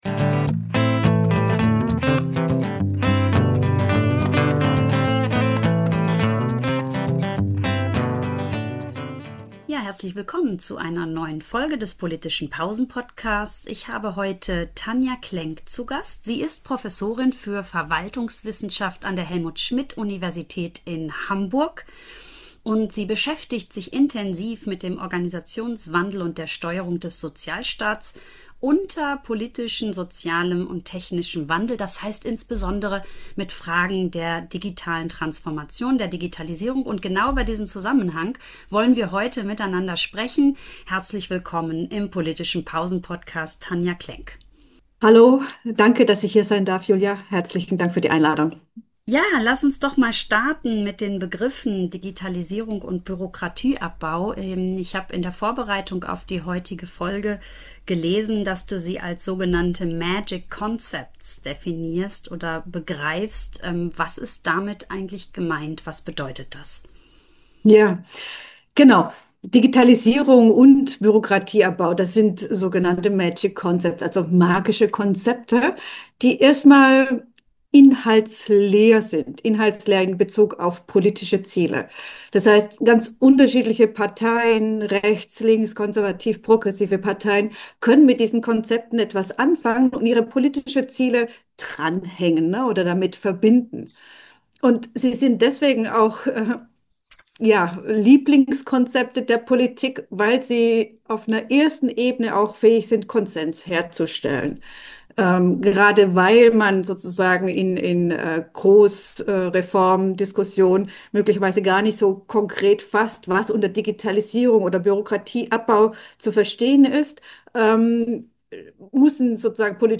Dabei wird deutlich, dass Digitalisierung kein Allheilmittel ist, sondern neue Ungleichheiten schafft. Ein Gespräch über politische Versprechen, soziale Teilhabe und die Frage, wie Verwaltungserfahrungen das Vertrauen in Staat und Demokratie prägen.